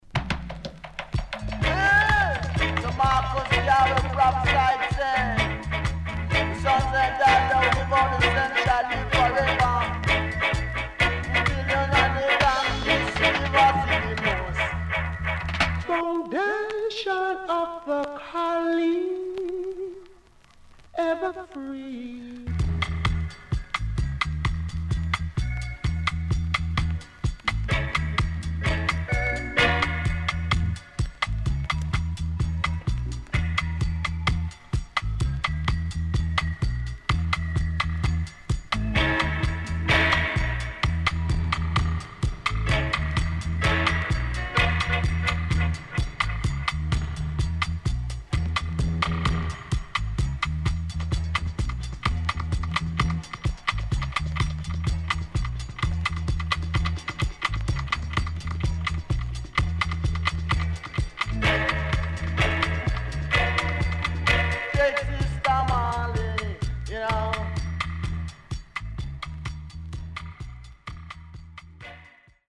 HOME > REGGAE / ROOTS  >  70’s DEEJAY
CONDITION SIDE A:VG(OK)
SIDE A:全体的にチリノイズがあり、少しプチノイズ入ります。